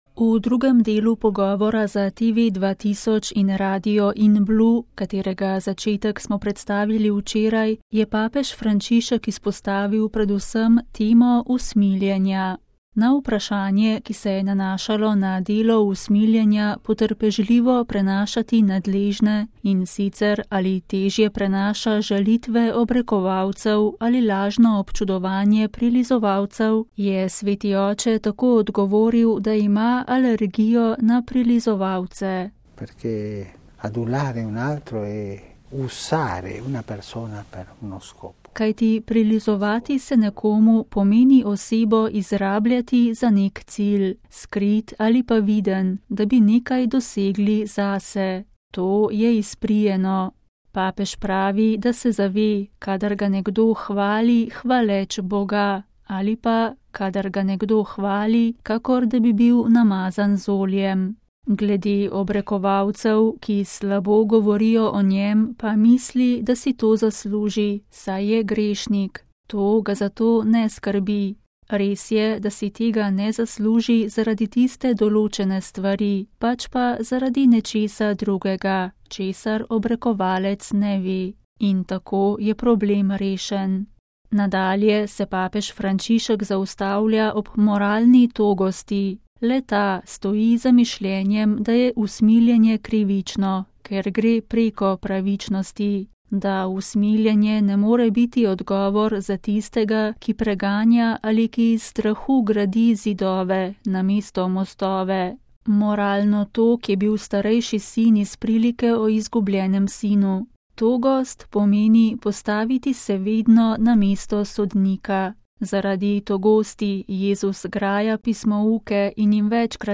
Papež Frančišek v pogovoru za Tv 2000 in Radio Inblu
VATIKAN (ponedeljek, 21. november 2016, RV) – Sadovi jubileja, odnos med usmiljenjem in pravičnostjo, srečanja z nekdanjimi prostitutkami, neozdravljivimi bolniki in zaporniki, skrivnost, kako se navkljub prenapolnjenim urnikom izogniti stresu: vse to so bile teme, ki se jih je ob zaključku svetega leta dotaknil papež Frančišek v 40-minutnem pogovoru za Tv 2000 in Radio Inblu. Intervju je bil predvajan v nedeljo zvečer.